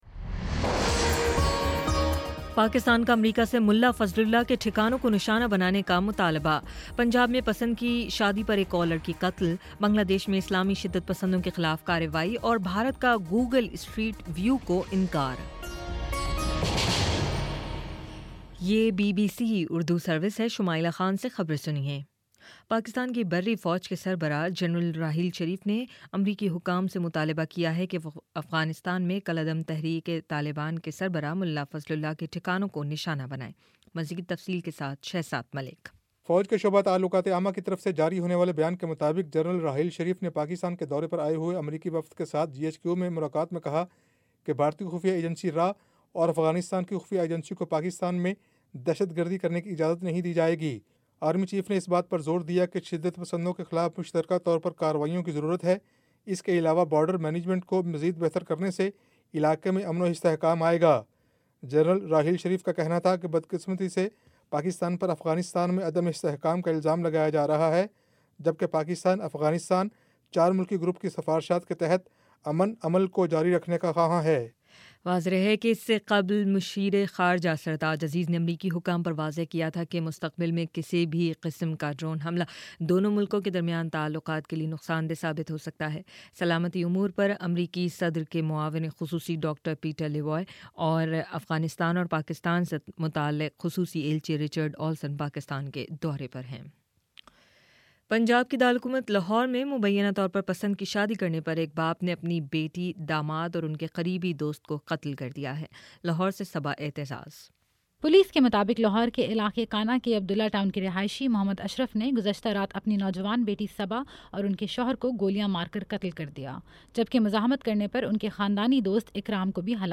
جون 10 : شام سات بجے کا نیوز بُلیٹن